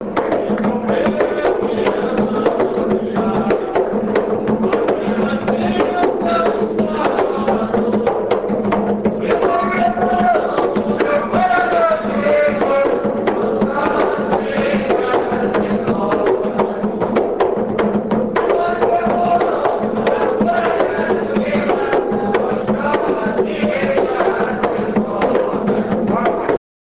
Pontos Cantados de Caboclos
Todos os pontos aqui apresentados foram gravados ao vivo.